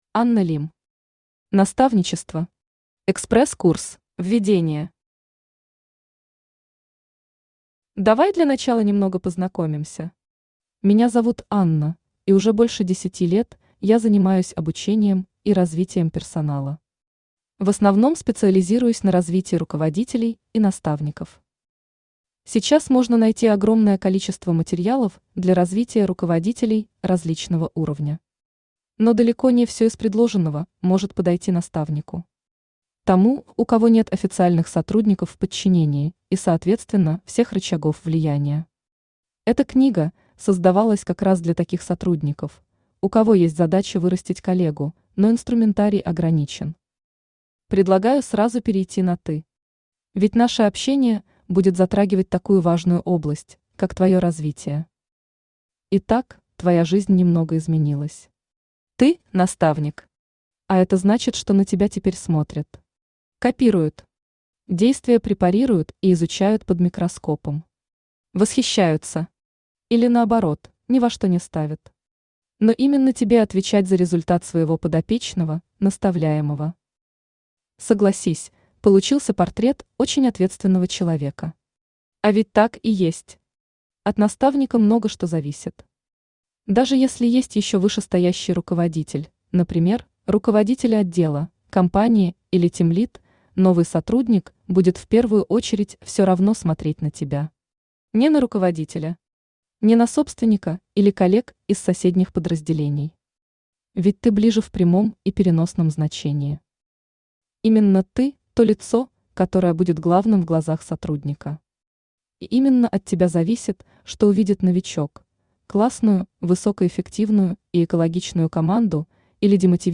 Аудиокнига Наставничество. Экспресс-курс | Библиотека аудиокниг
Экспресс-курс Автор Анна Лим Читает аудиокнигу Авточтец ЛитРес.